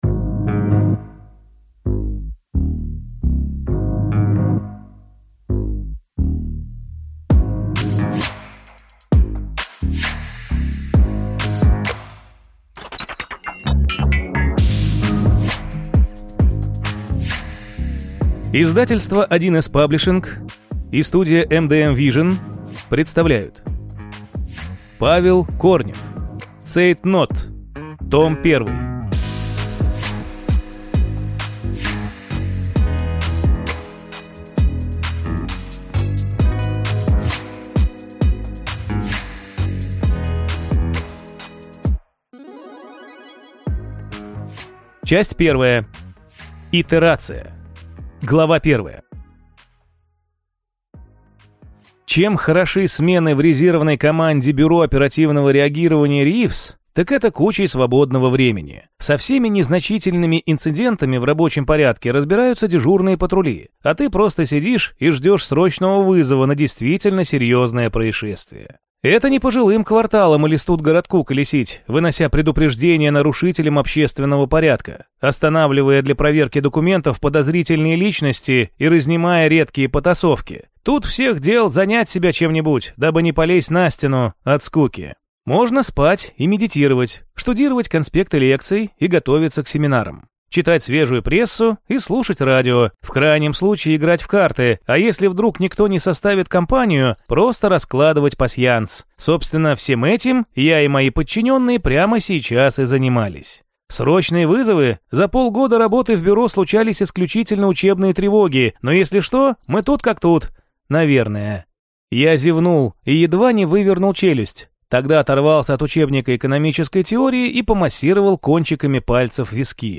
Аудиокнига Цейтнот. Том 1 | Библиотека аудиокниг
Прослушать и бесплатно скачать фрагмент аудиокниги